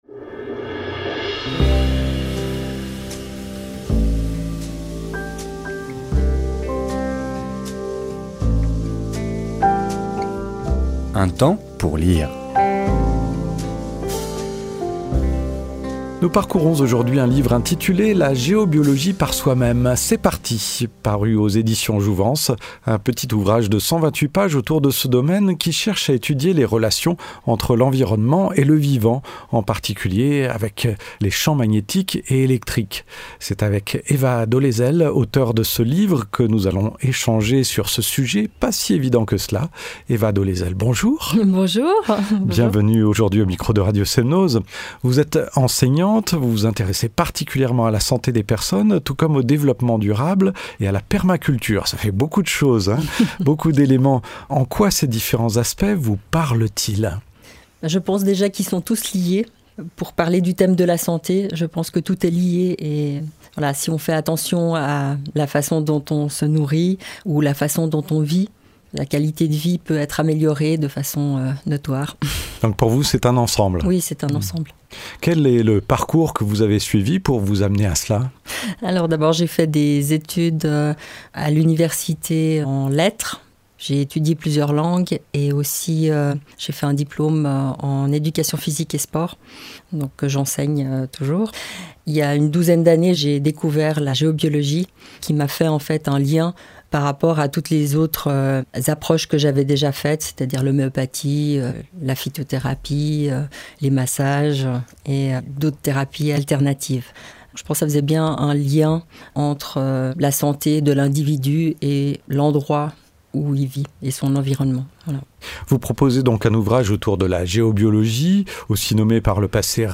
Une interview radio